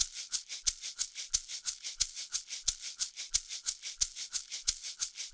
Shaker 03.wav